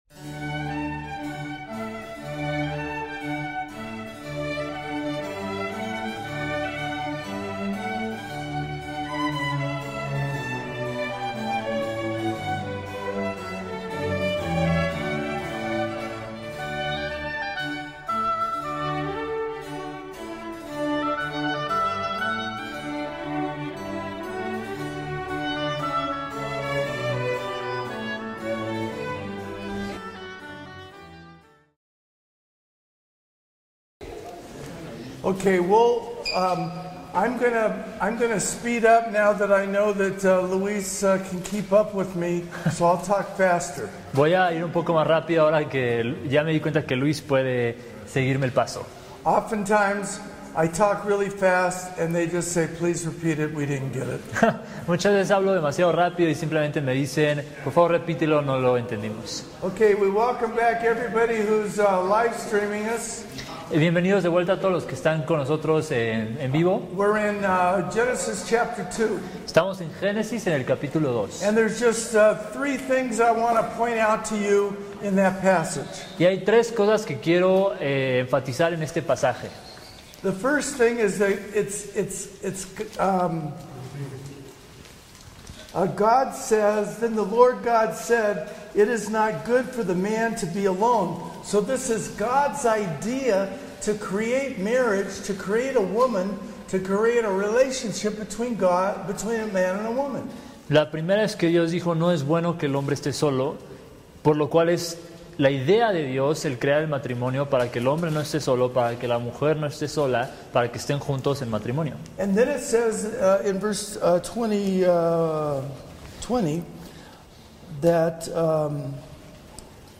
Conferencia Matrimonio 2